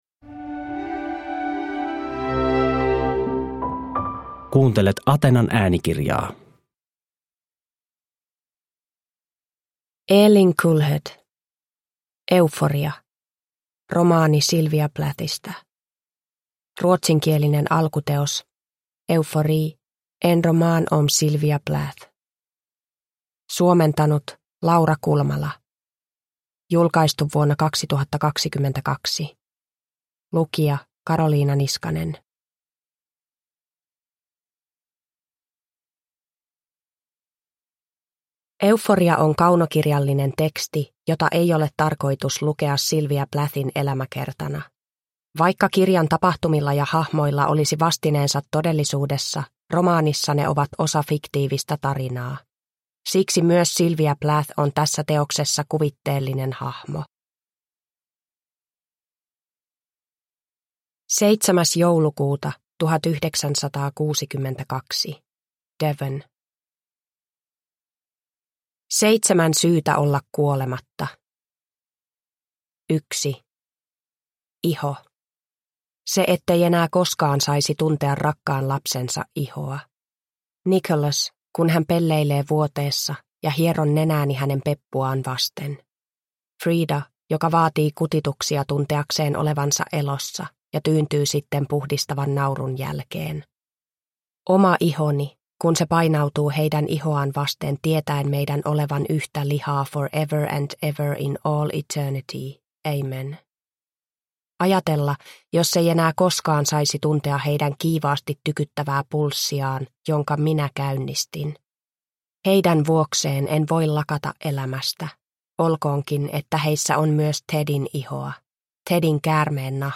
Euforia – Ljudbok – Laddas ner